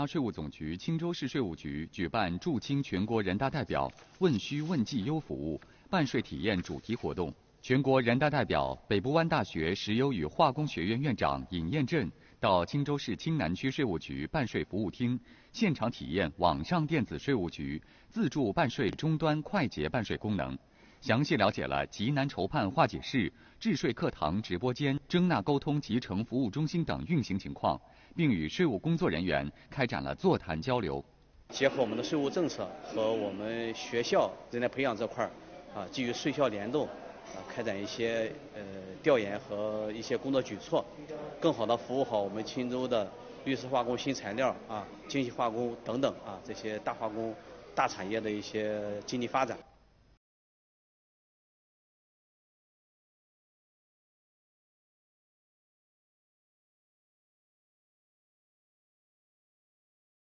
【电视报道】乘便民办税“春风” 税校联动激活发展